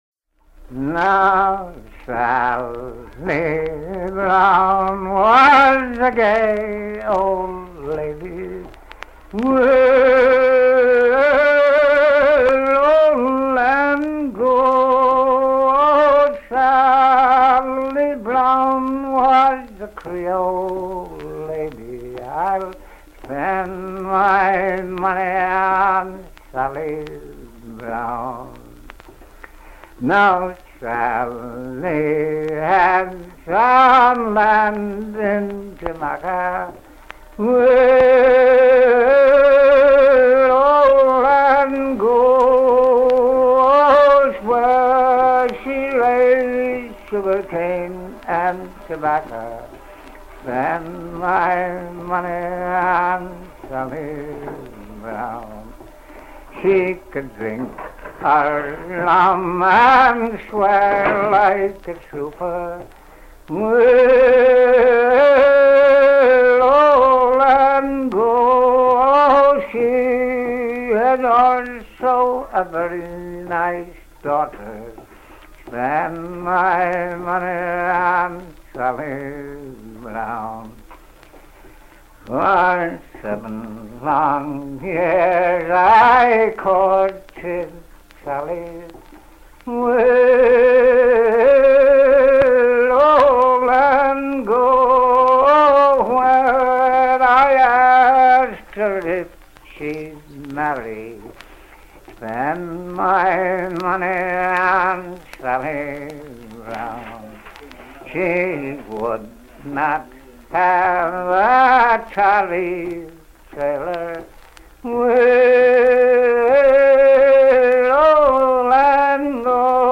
enregistré à Sailor Snug Harbor, Staten Island, New-York
à virer au cabestan
maritimes
Genre strophique